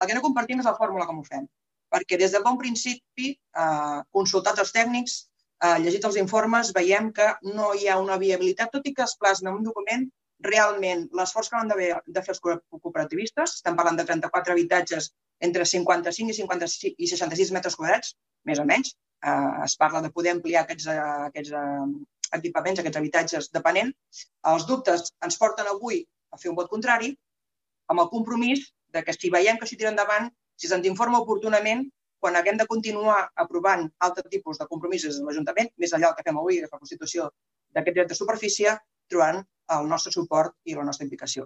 Per la seva banda el PSC també hi va votar en contra, tal i com ho va expressar la regidora Raquel Gallego.